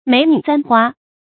美女簪花 注音： ㄇㄟˇ ㄋㄩˇ ㄗㄢ ㄏㄨㄚ 讀音讀法： 意思解釋： 簪：插戴。形容書法娟秀。也比喻詩文清新秀麗。